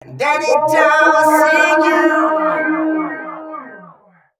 006 male.wav